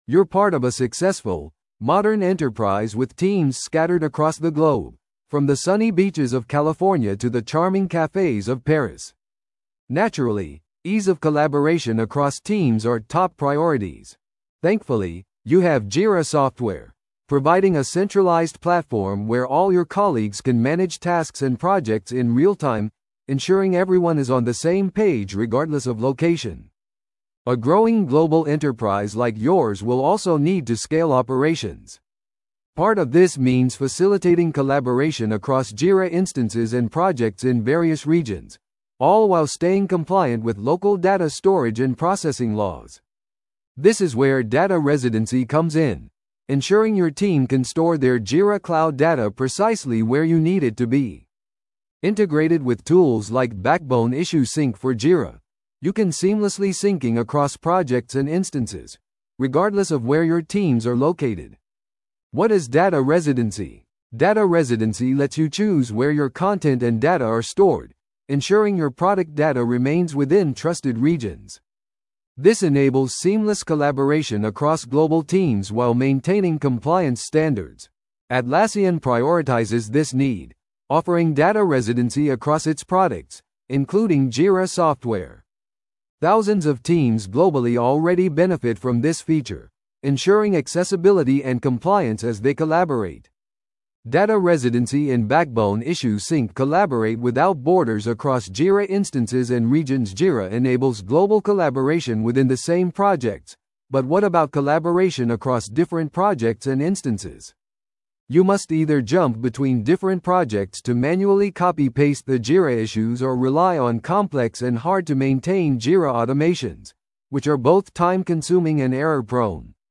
textToSpeech